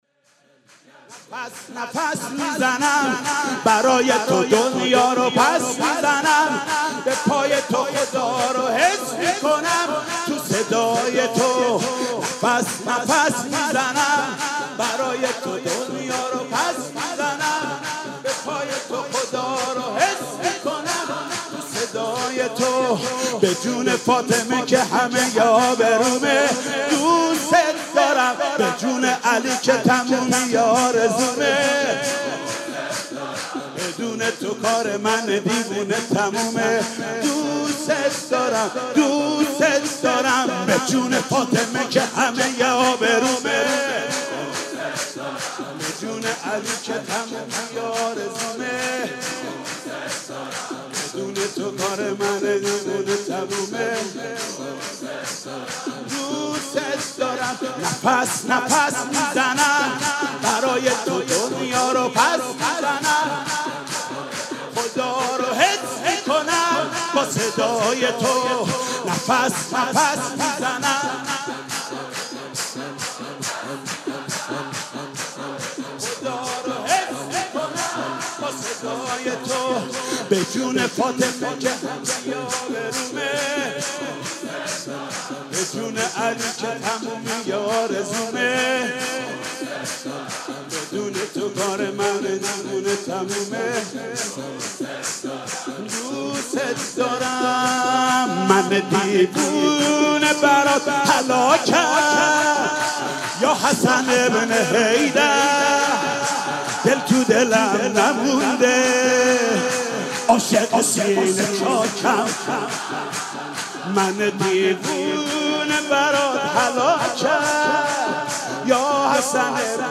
سرود: نفس نفس میزنم برای تو